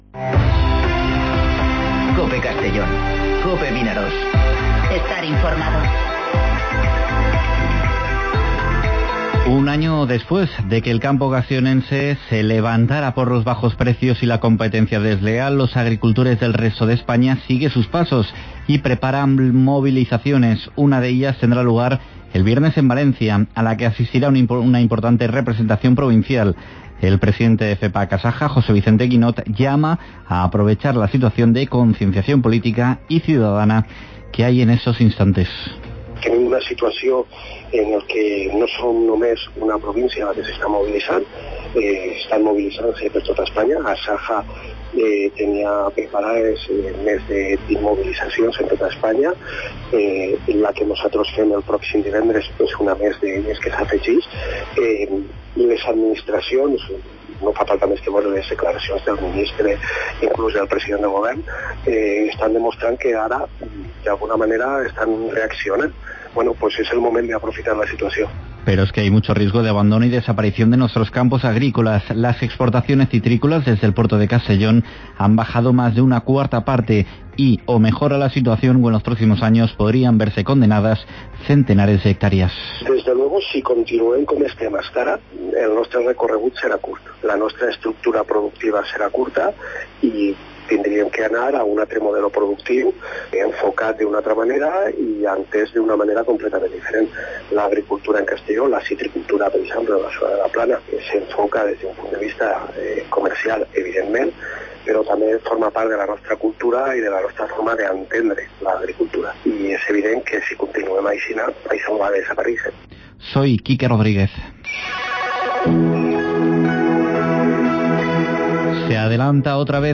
Informativo Mediodía COPE en Castellón (10/02/2020)